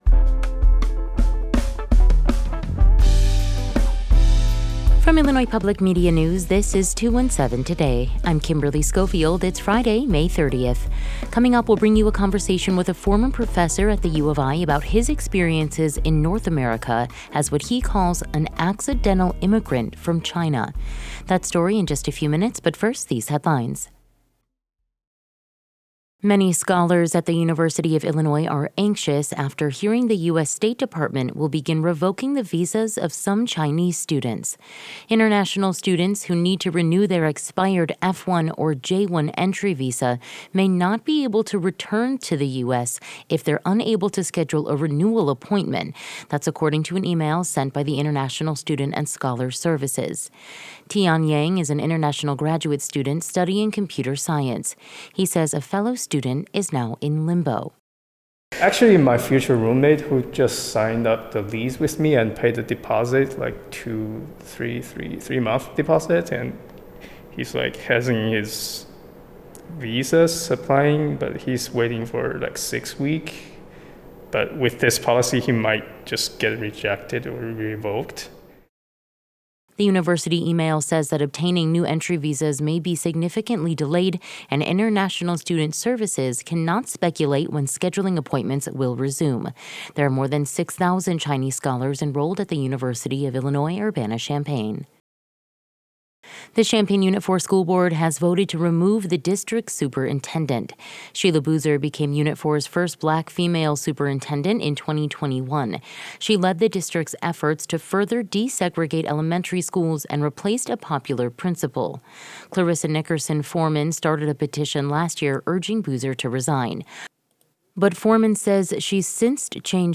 In today's deep dive, we bring you a conversation with a former professor at the U of I about his experiences in North America as what he calls "an accidental immigrant" from China.